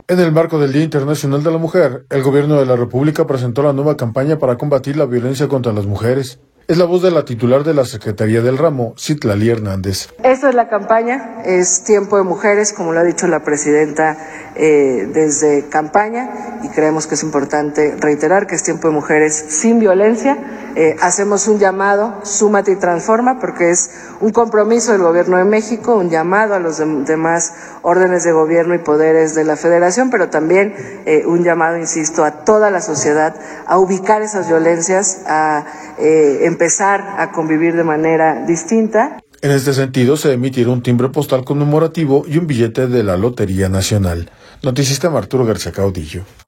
En el marco del Día Internacional de la Mujer, el Gobierno de la República presentó la nueva campaña para combatir la violencia contra las mujeres. Es la voz de la titular de la Secretaría del ramo, Citlali Hernández.